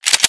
pulllever.wav